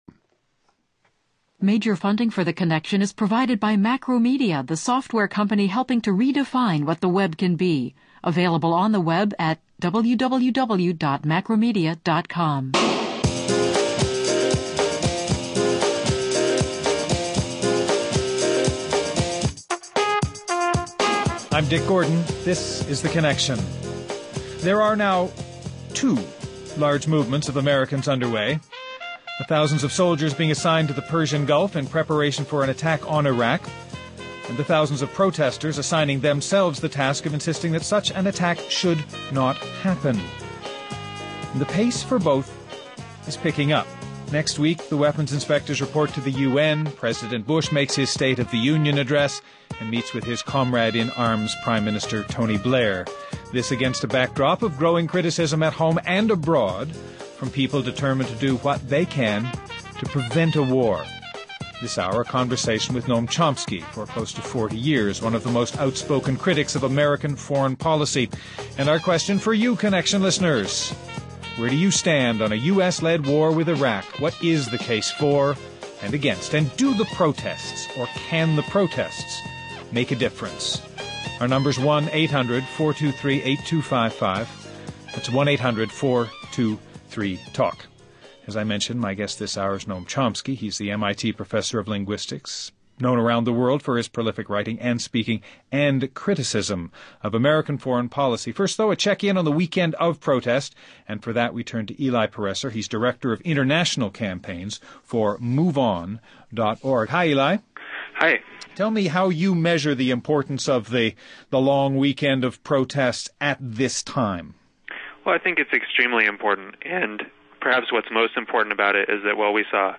Guests: Noam Chomsky, professor of linguistics at MIT.